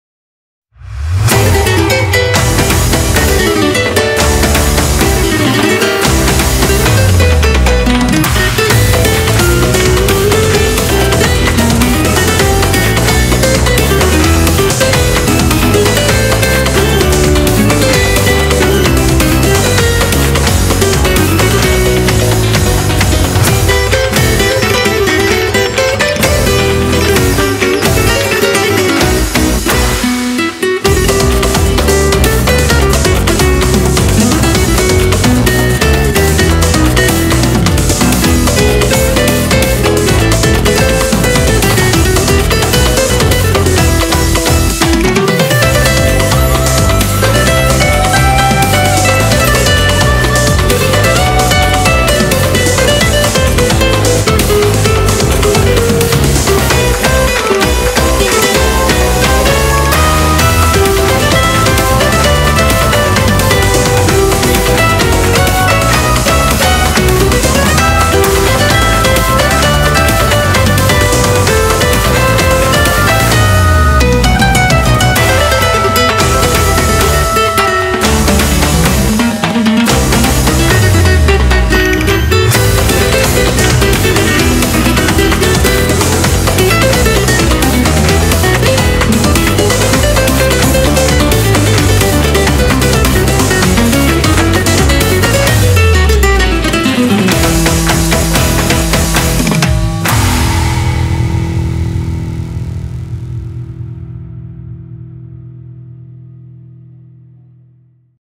BPM130
Audio QualityPerfect (High Quality)
Comments[SPANISH GUITAR]